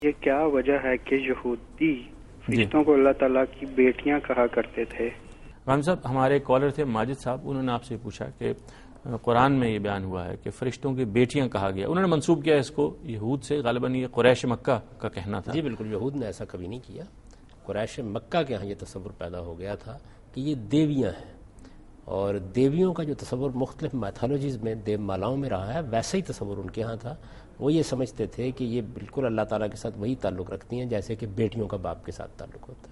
Category: TV Programs / Dunya News / Deen-o-Daanish / Questions_Answers /